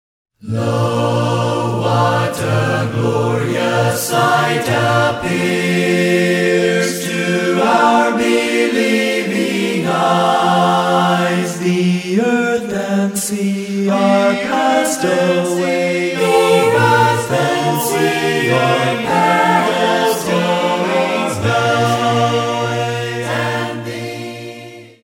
singing 16 hymns a cappella.